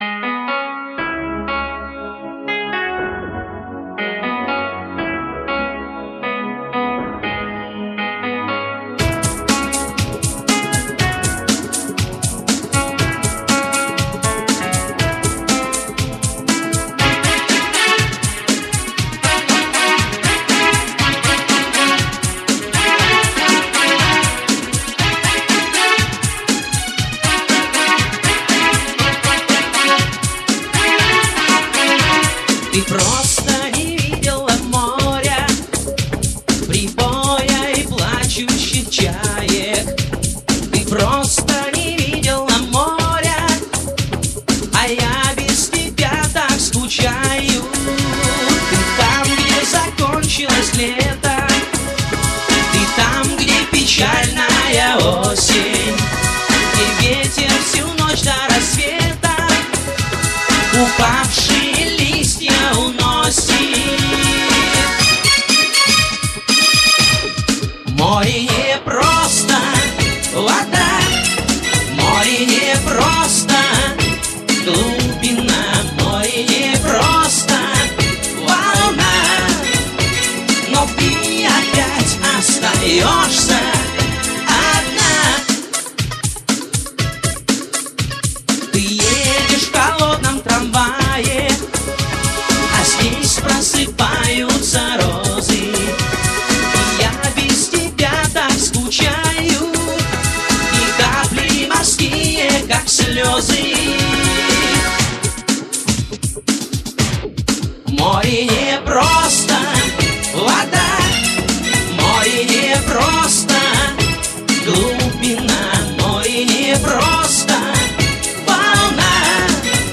Жанр: поп, поп-музыка